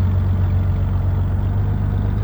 idle.wav